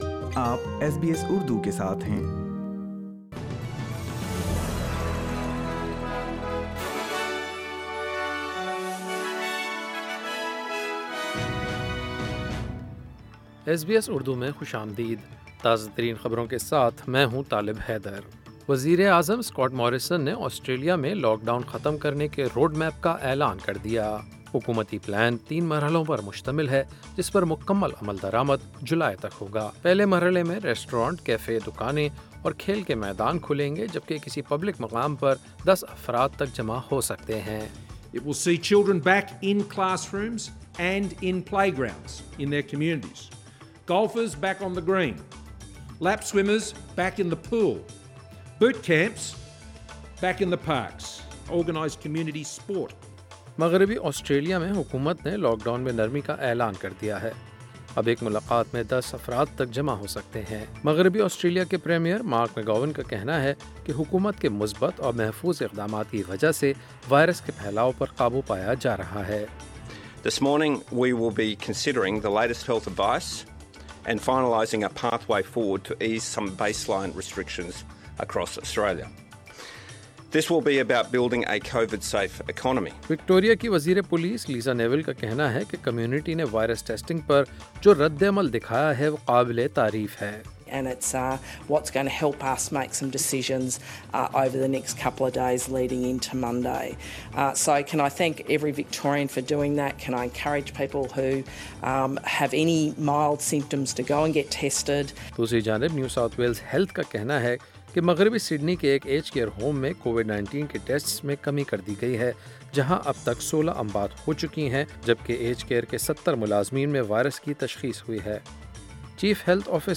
SBS Urdu News 8 May 2020